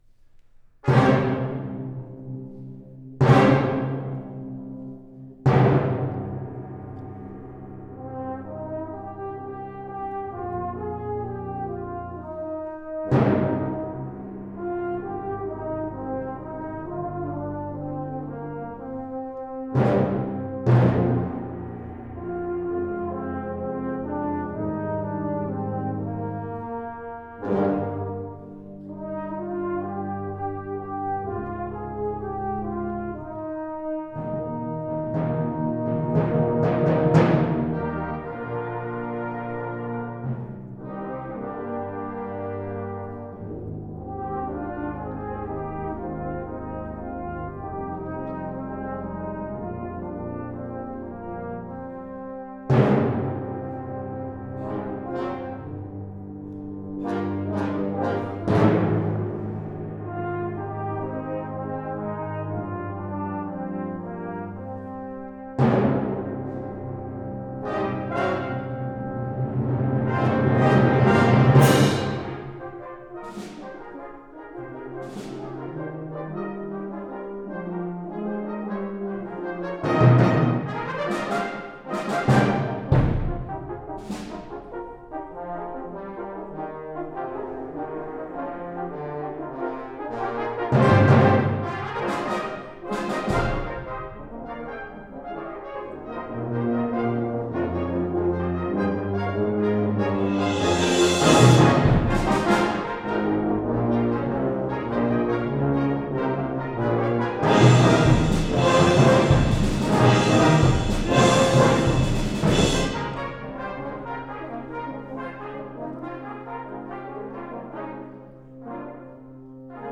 Holiday Concerts